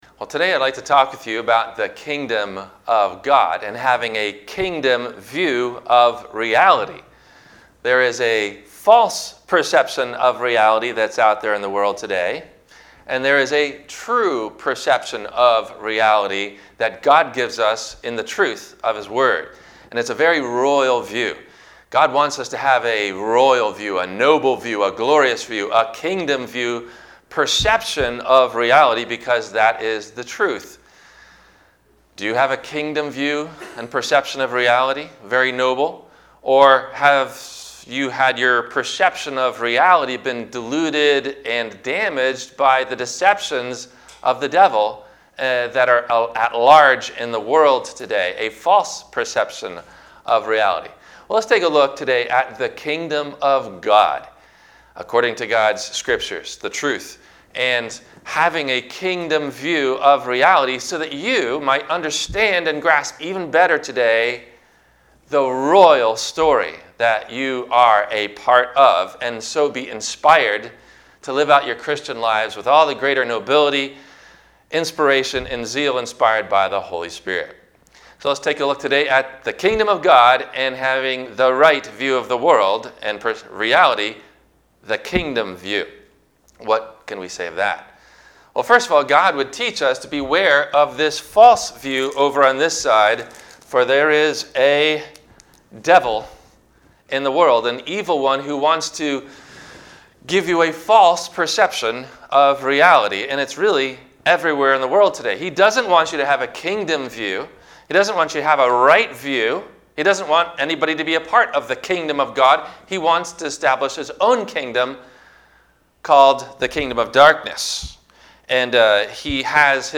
The Kingdom of God – WMIE Radio Sermon – September 22 2025